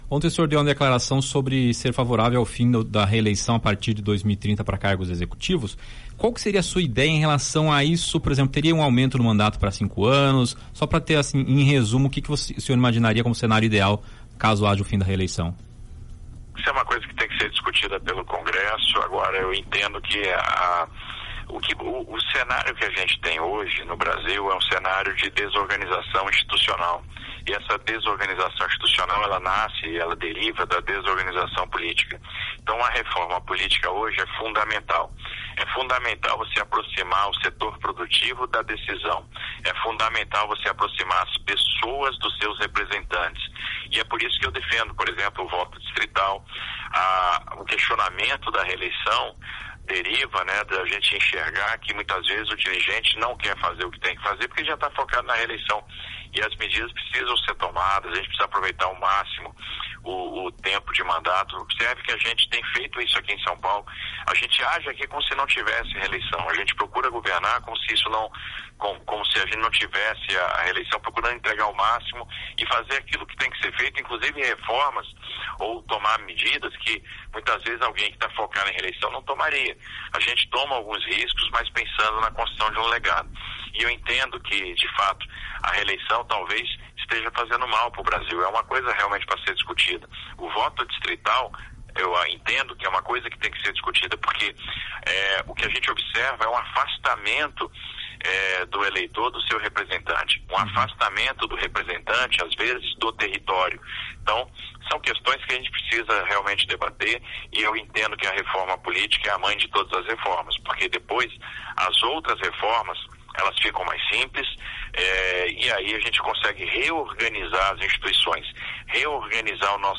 Tarcísio defende fim da reeleição e propõe debate sobre reforma política em entrevista à Jovem Pan News Campinas